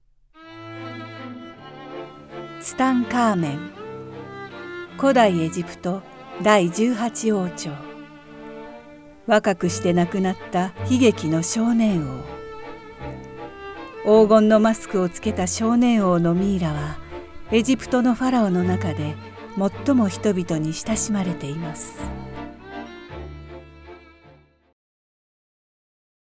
ナレーション
ボイスサンプル